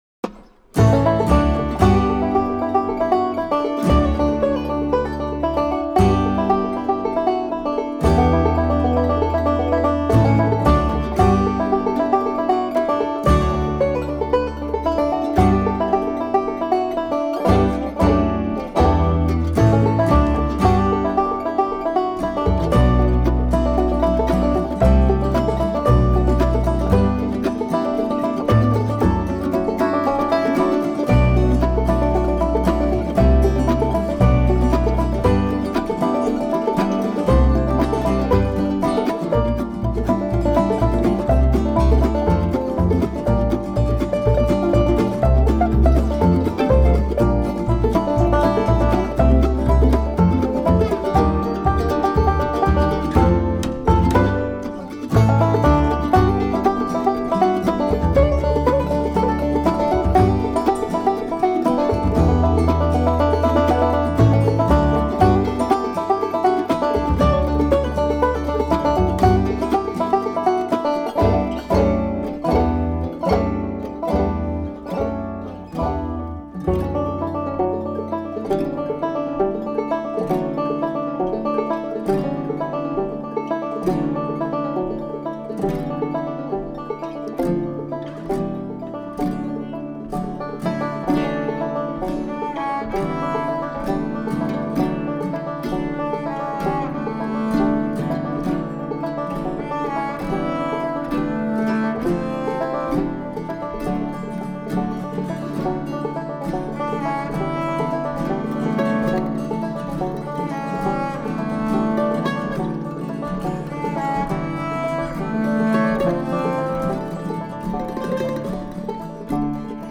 mandolin
banjo
guitar and piano
double bass